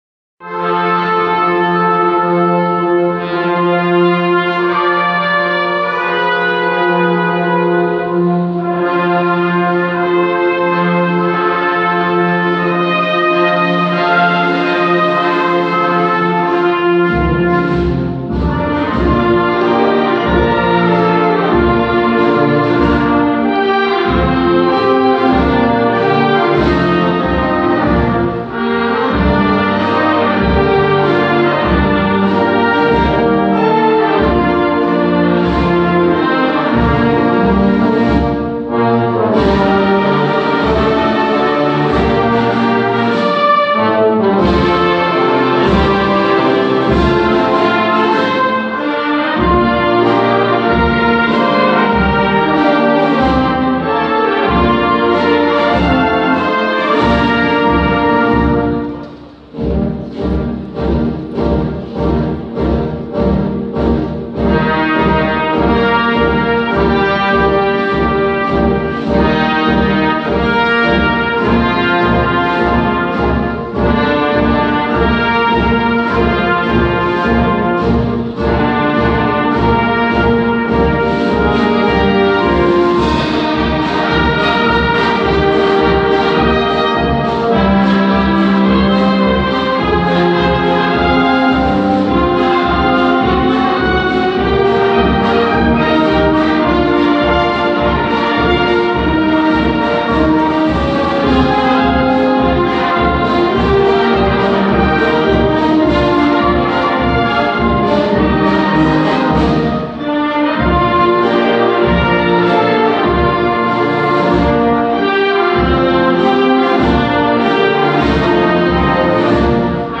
AUDIO (cantato)